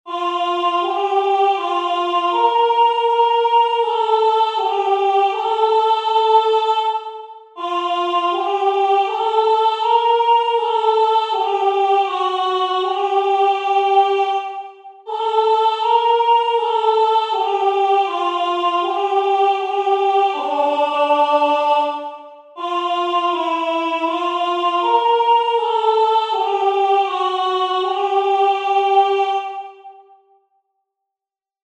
Skaņdarbs lieliski piemērots senās mūzikas praktizēšanai, visas balsis dzied vienā ritmā.
O.Salutaris-Superius.mp3